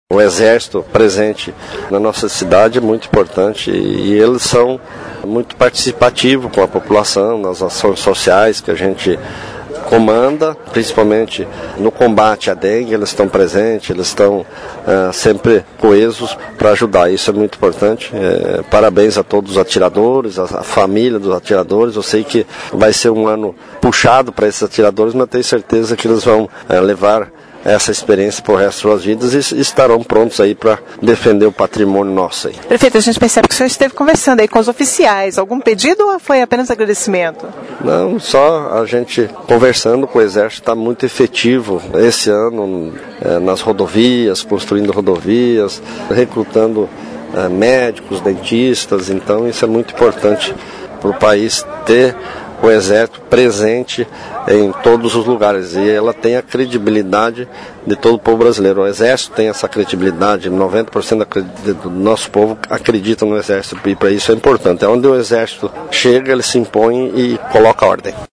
O Diretor do Tiro de Guerra, o prefeito Maurí José Alves, comenta a respeito deste contato com o Exército Brasileiro.